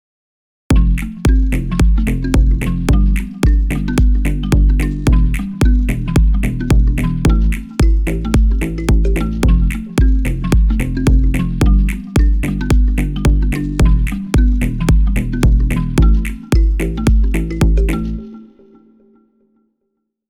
ベースと言えばTrilian。Trilianと言えば「Clubbing Swedes 1」だね。
カッコイイ音色でお気に入り。
ベースのトラックを作成。
パターンジェネレーターのベースプリセットをRapidに選択。